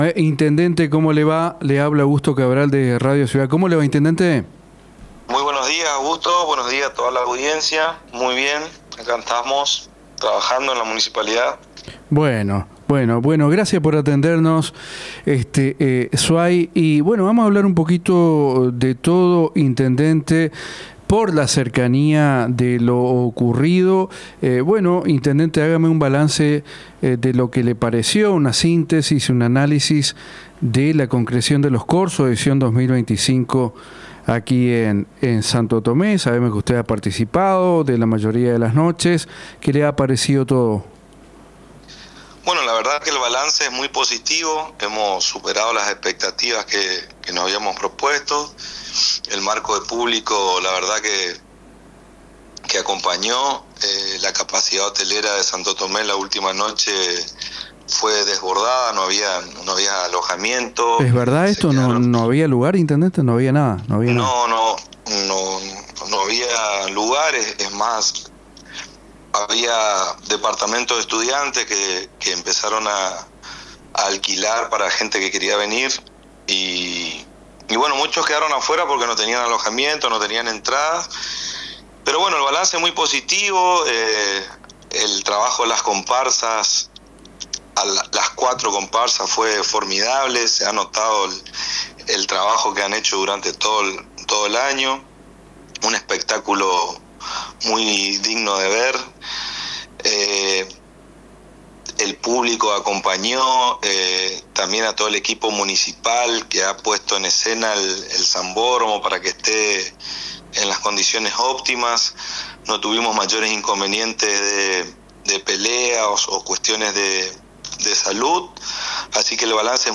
En una entrevista brindada a Radio Ciudad está mañana , el primer mandatario comunal afirmó que el Municipio conversa con varias empresas que este año podrían afincarse en Santo Tomé.
En materia educativa, el intendente deslizó la posibilidad que se instale la carrera de Psicopedagogía y Recursos humanos en Santo Tomé. Escuche la entrevista completa en Urgente.